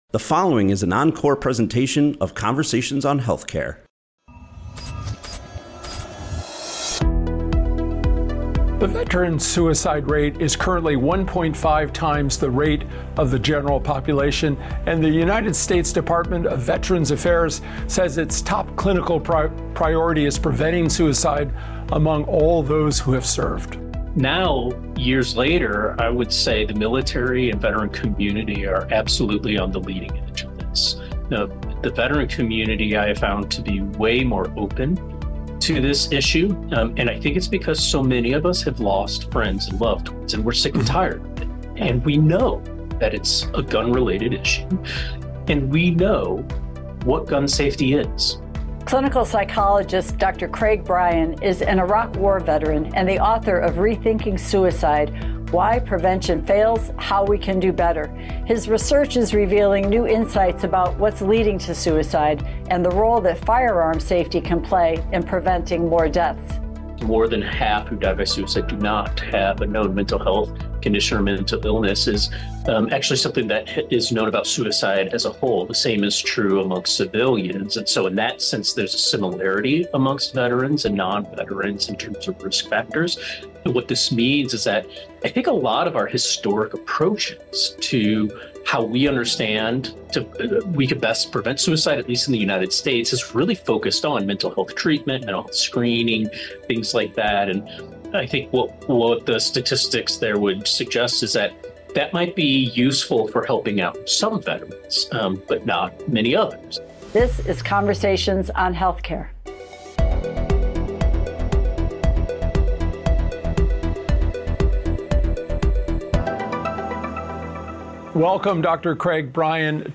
clinical psychologist, Iraq War veteran